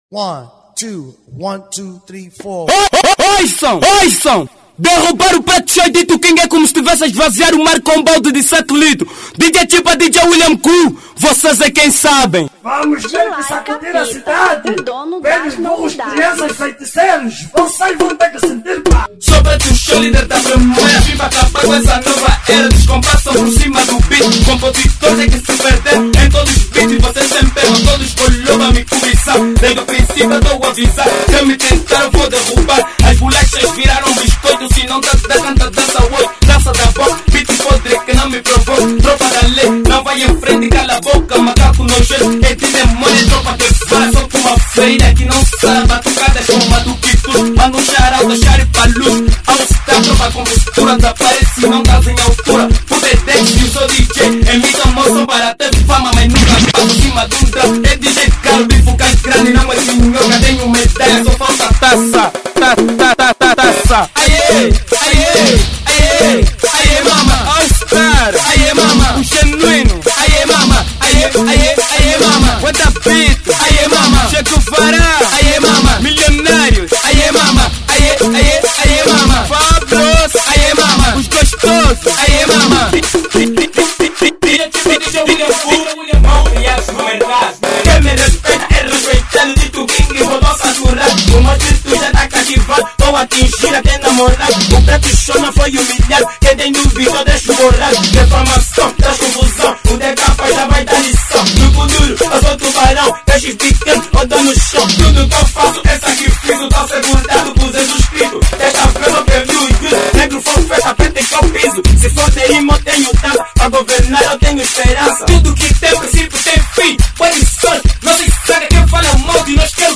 Kuduro 2008